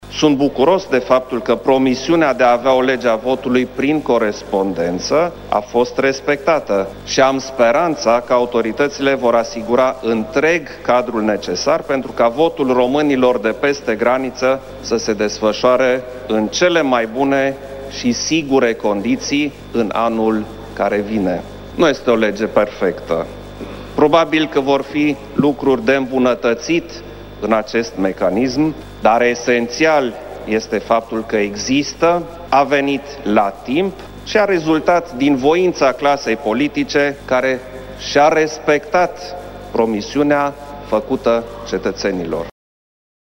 Președintele Klaus Iohannis s-a adresat Parlamentului